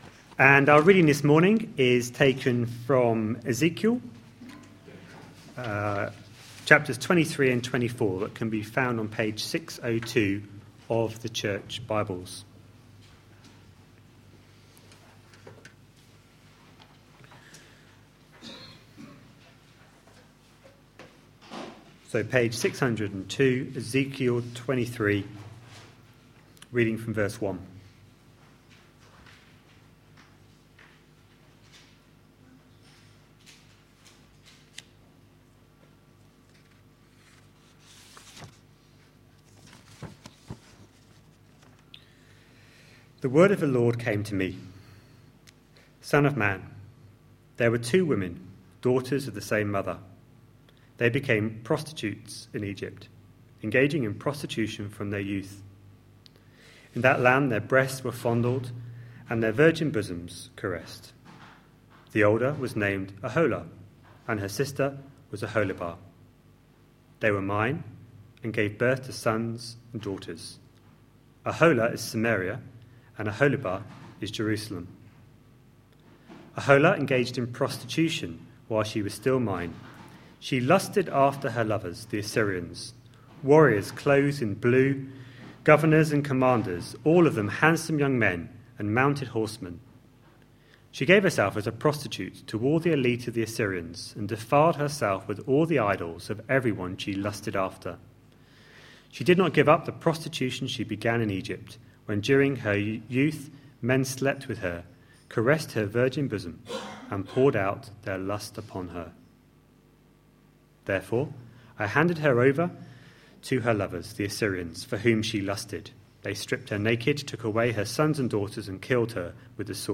A sermon preached on 13th July, 2014, as part of our Ezekiel series.